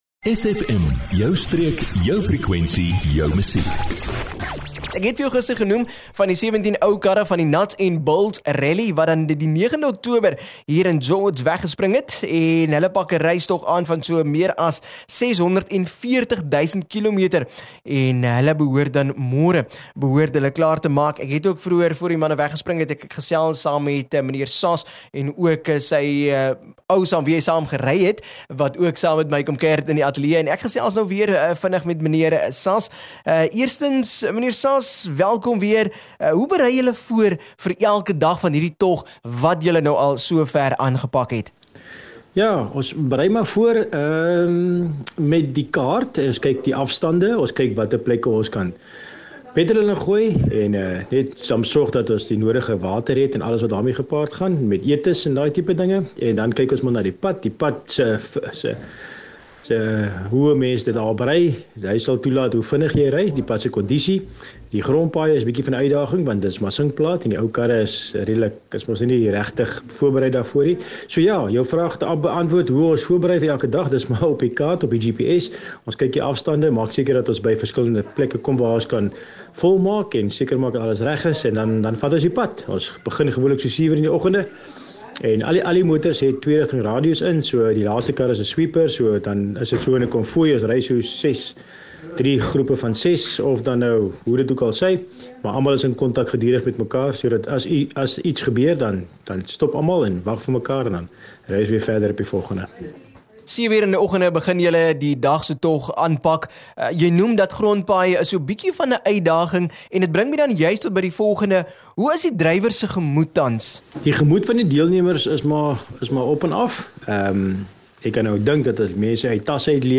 gesels via foon